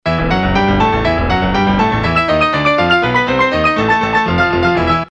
A good example of keyboard double octaves played in the style of Chopin or Liszt. The algorithm is based on fractal geometry utilizing Julia sets.